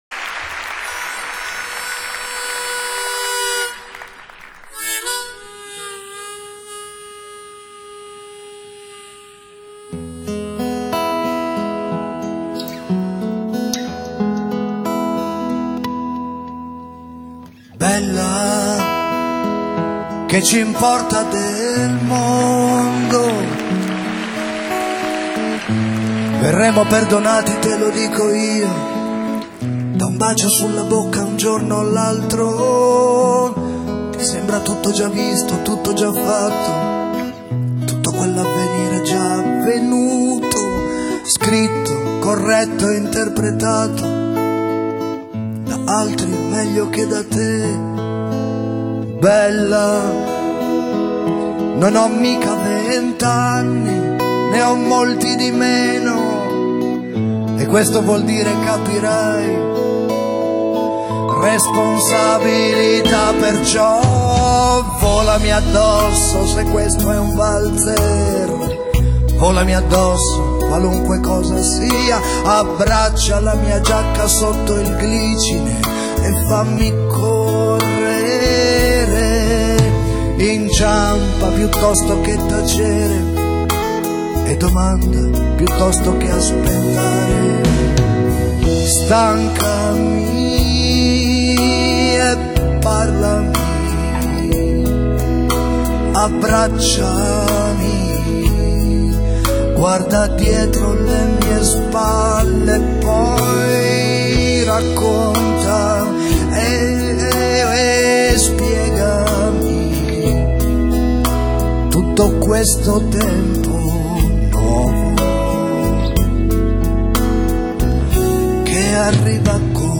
Musica di sottofondo